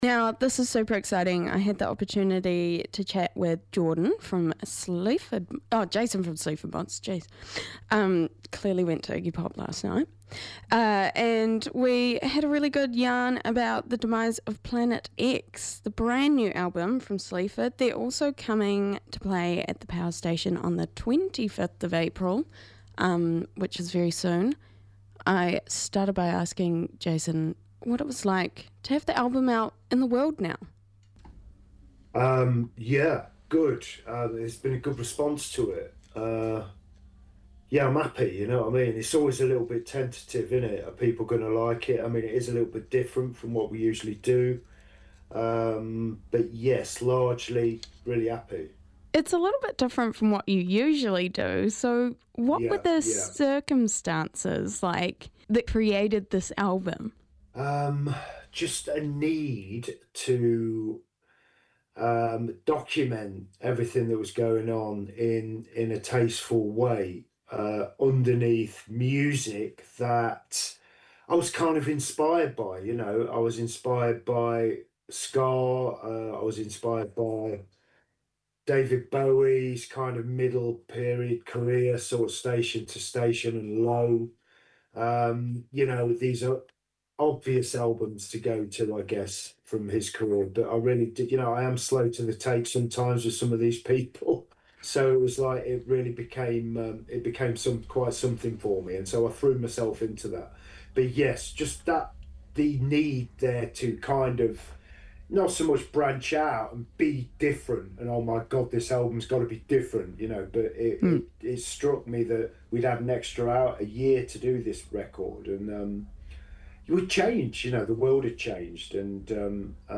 Guest Interview with Sleaford Mods: Rāmere January 30, 2026